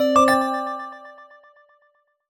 jingle_chime_03_positive.wav